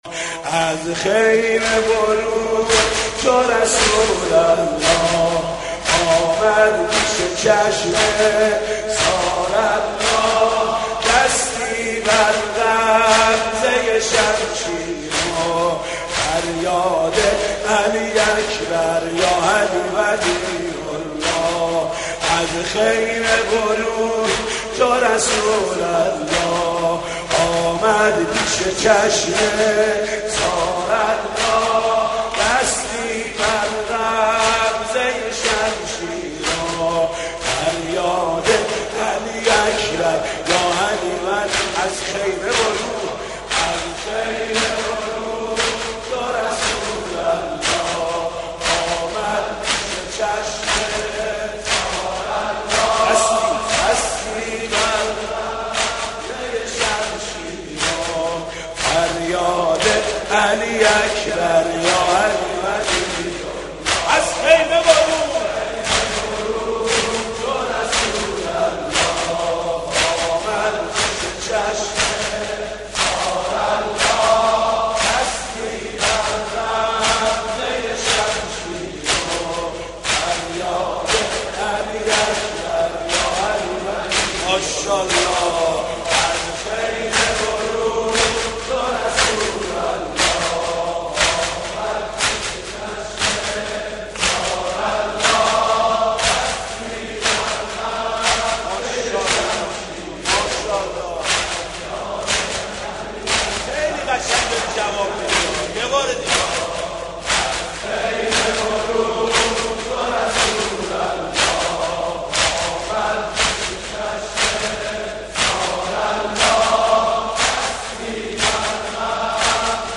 نوحه ماندگار